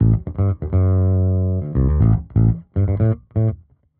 Index of /musicradar/dusty-funk-samples/Bass/120bpm
DF_JaBass_120-A.wav